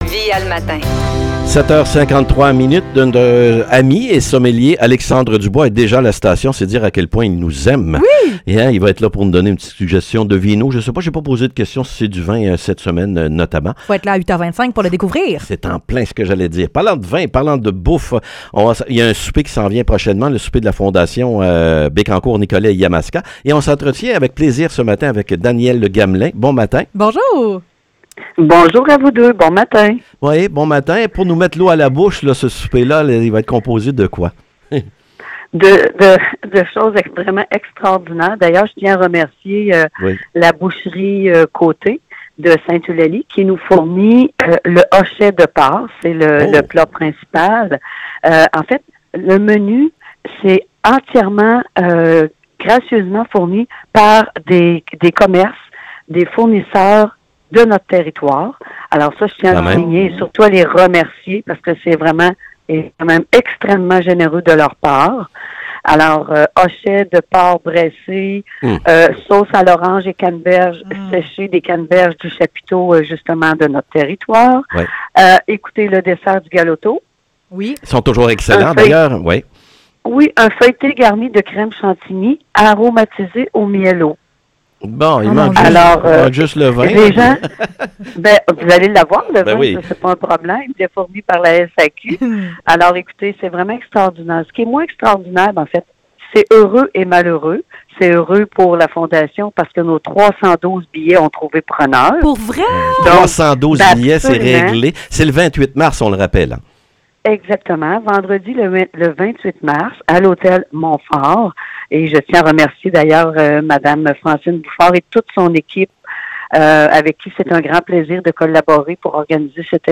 Entrevue pour la Fondation Santé BNY